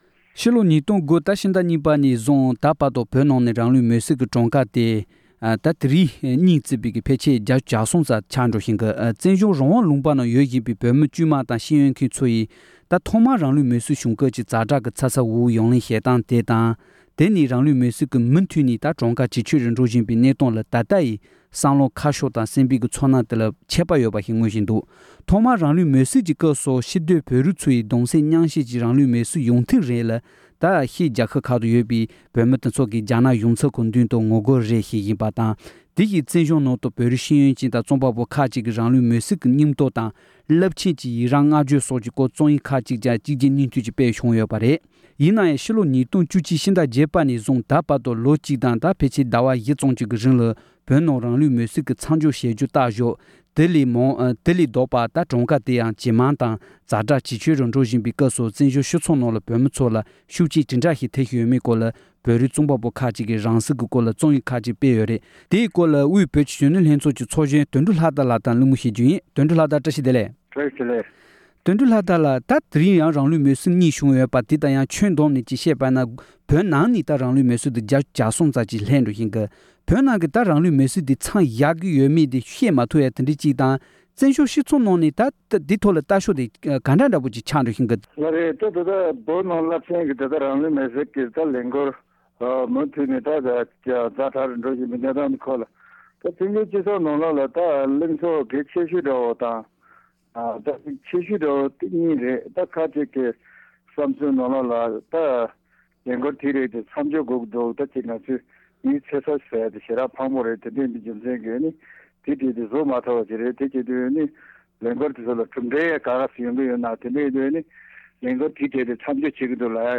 བཙན་འབྱོལ་བོད་མིའི་སྤྱི་ཚོགས་ནང་རང་ལུས་མེ་སྲེག་སྐོར་བསམ་ཚུལ་འདྲ་མིན་གྱི་རྩོམ་ཡིག་སྤེལ་ཡོད་པ་ཁག་གི་ཐོག་གླེང་མོལ།